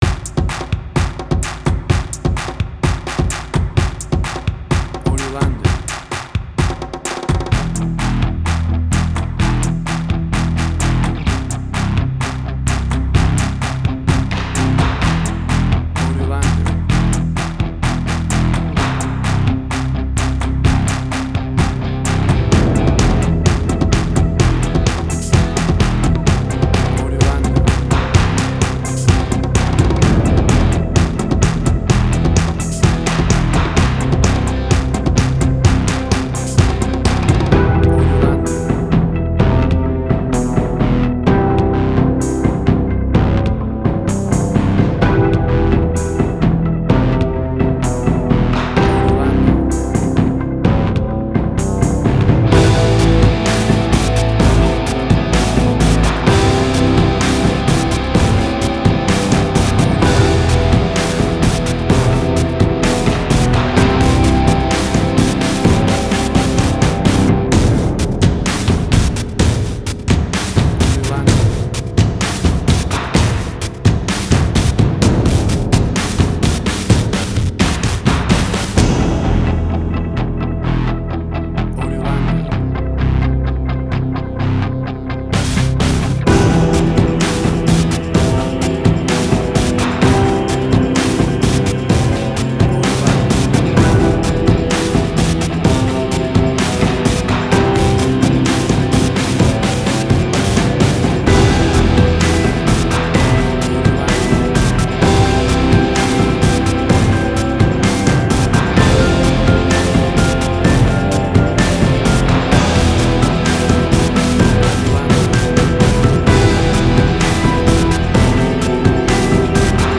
Suspense, Drama, Quirky, Emotional.
WAV Sample Rate: 16-Bit stereo, 44.1 kHz
Tempo (BPM): 128